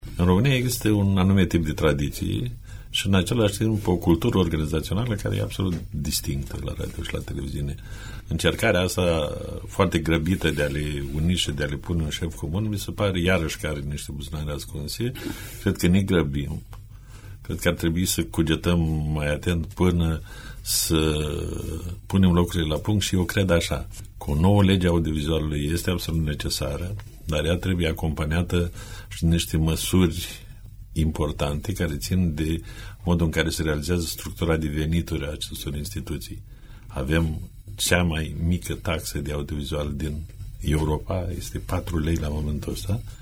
Propunerea de modificare a Legii 41, de organizare şi funcţionare a societăţilor publice de radio şi televiziune, a fost, astăzi, subiect de dezbatere la Radio Iaşi.